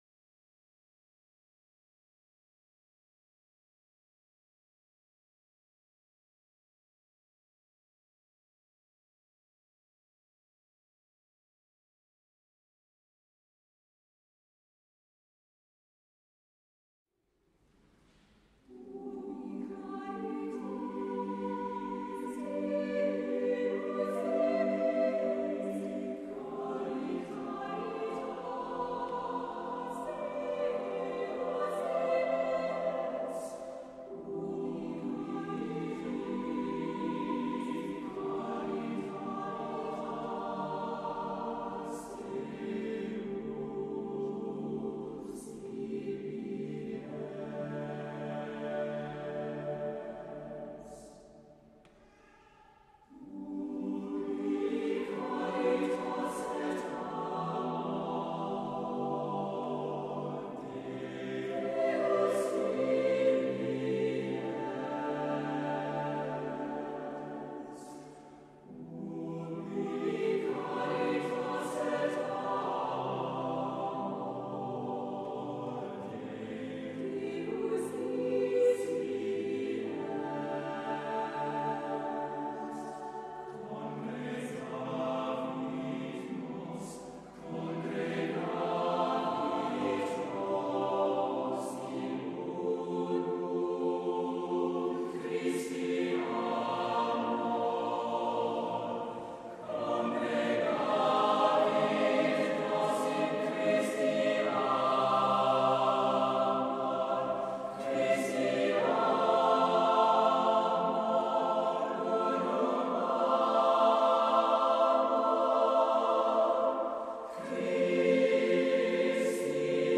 Accompaniment:      A Cappella
Music Category:      Choral